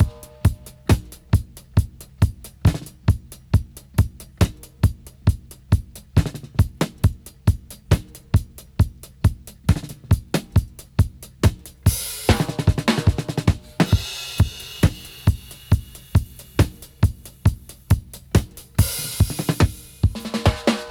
136-DRY-03.wav